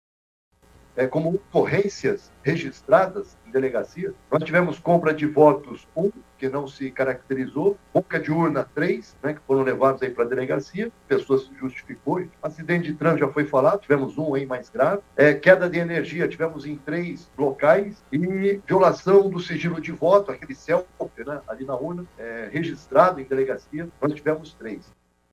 Durante coletiva de imprensa, órgãos envolvidos nas Eleições 2022 divulgaram dados.
Sonora-general-Mansour-chefe-da-Secretaria-de-Seguranca-Publica-do-Amazonas.mp3